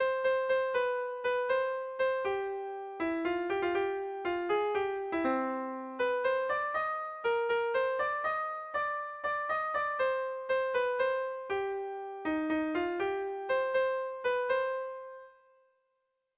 Erlijiozkoa